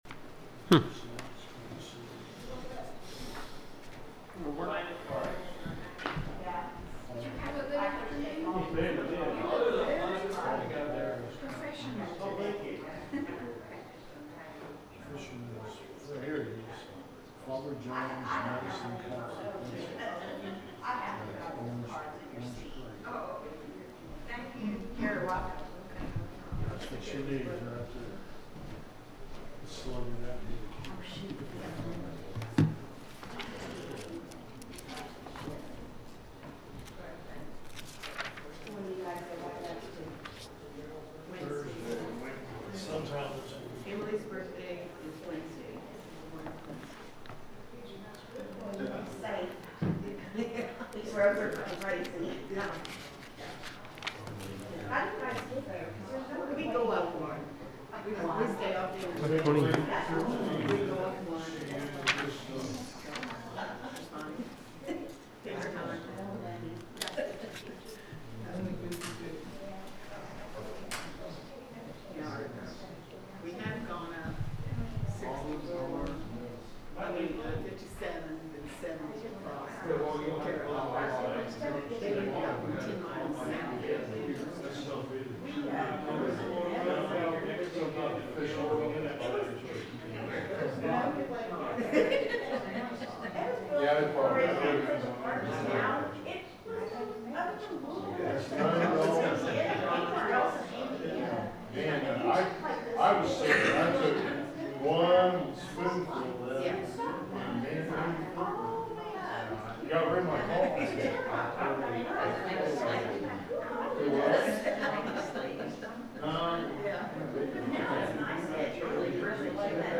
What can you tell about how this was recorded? The sermon is from our live stream on 12/7/2025